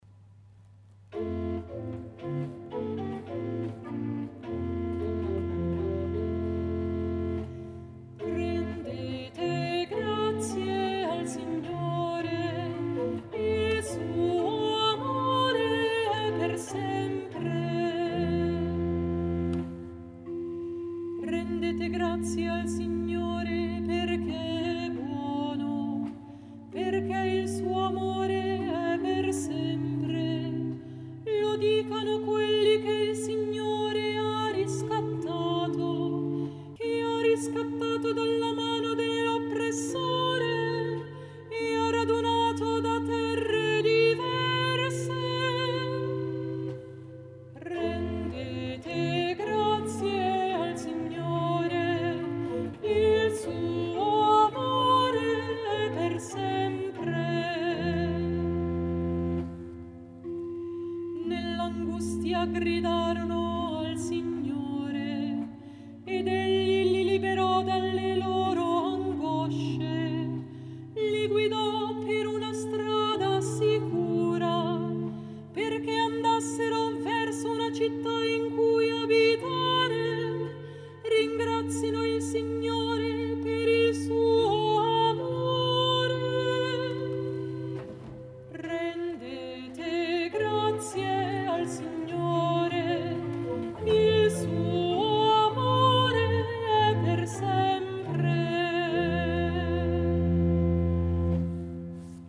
Rendete grazie al Signore, il suo amore è per sempre Versione per chitarra